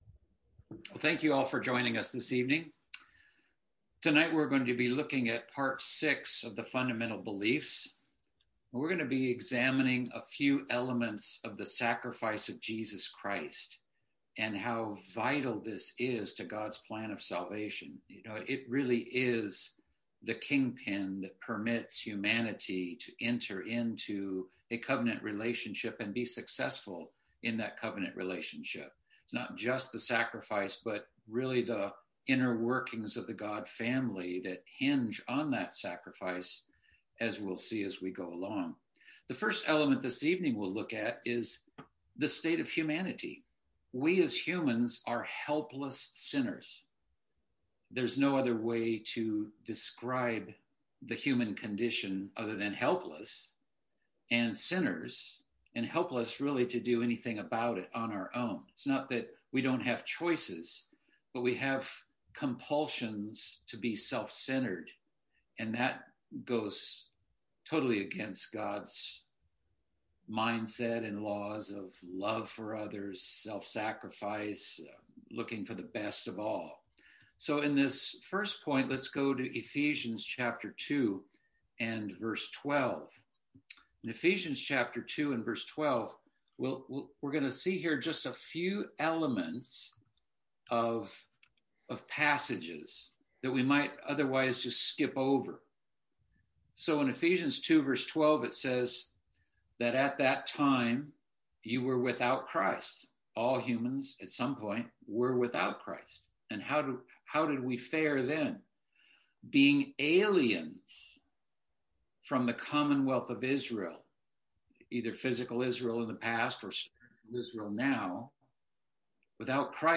Fundamental Biblical Beliefs - Bible Study - Part 6 - The Sacrifice of Jesus Christ